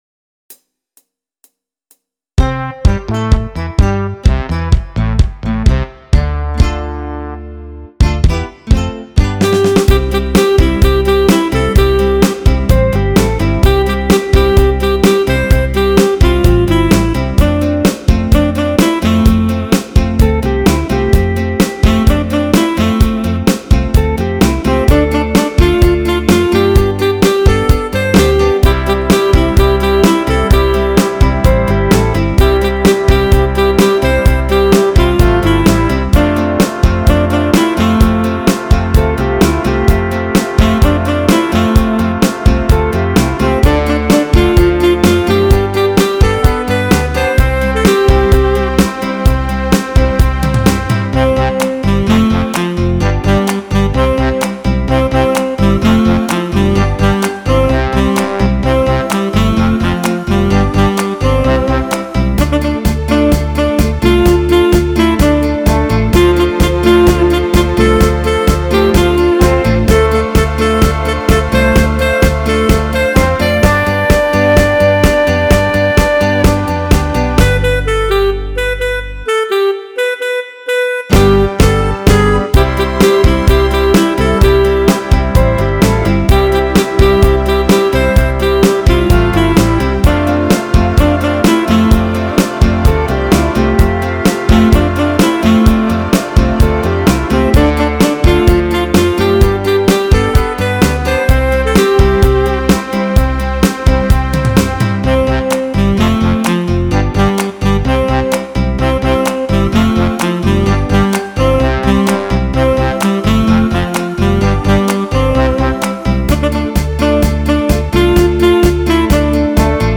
Still a good song with a great beat for your dancing crowd.